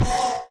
Sound / Minecraft / mob / horse / skeleton / hit1.ogg
hit1.ogg